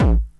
norkick.wav